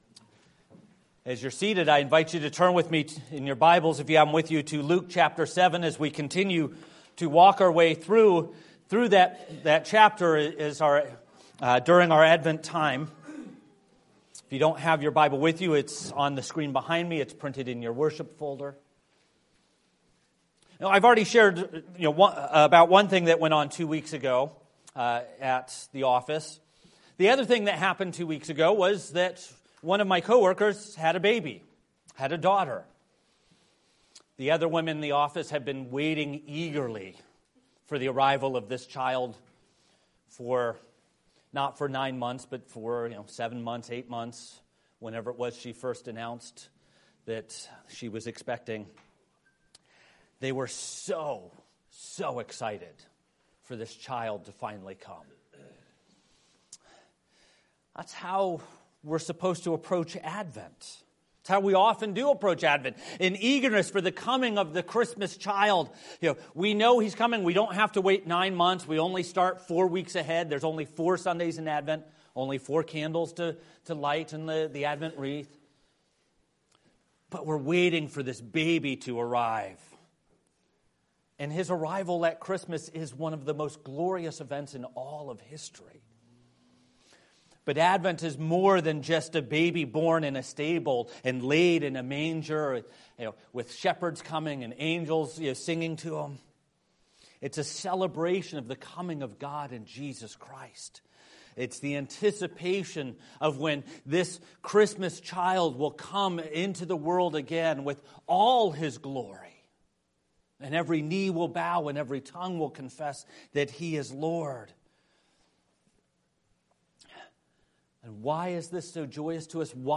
December 14, 2025 That You May Be Certain series Advent 2025 Save/Download this sermon Luke 7:18-35 Other sermons from Luke Messengers from John the Baptist 18 The disciples of John reported all these things to him.